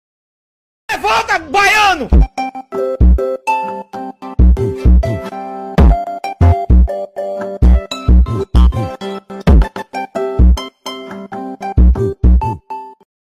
2025-02-16 16:37:18 Gênero: Funk Views